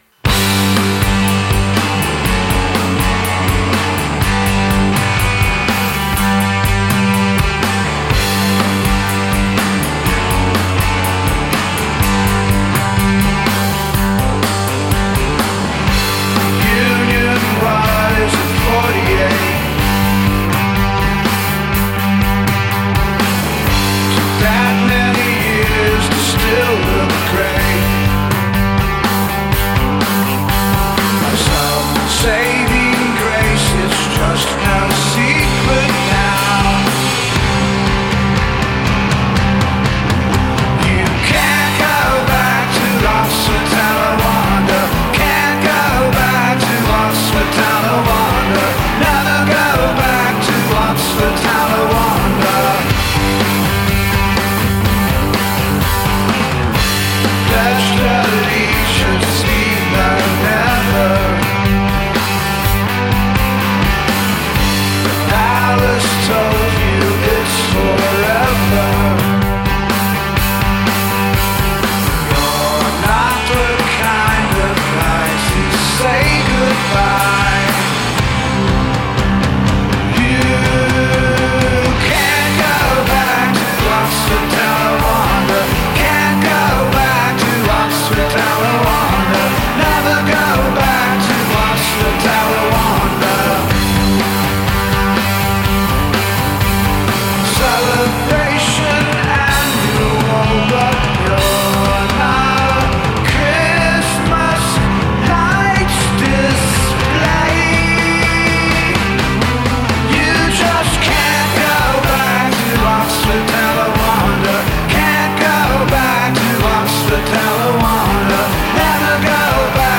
американской инди-рок-группы